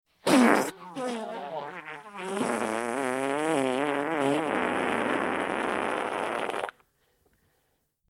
Very Wet Fart New - Bouton d'effet sonore